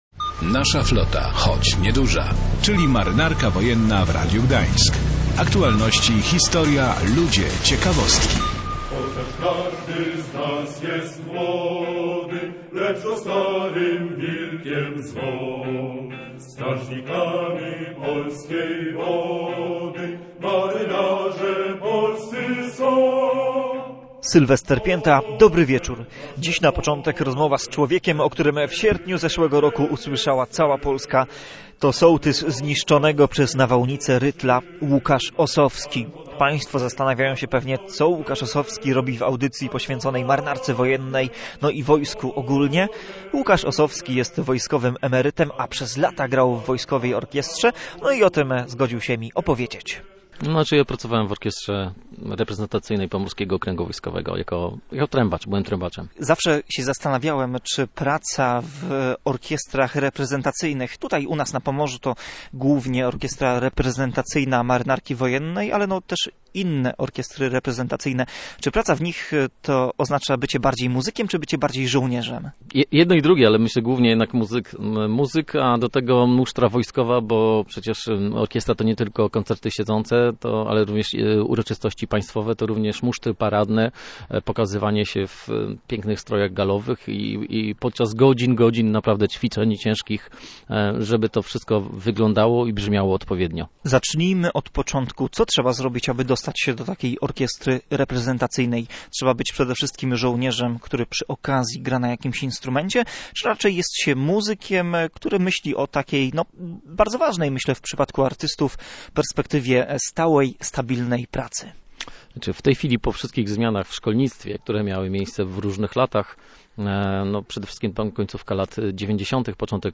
Na początek rozmowa z człowiekiem, o którym w sierpniu zeszłego roku usłyszała cała Polska.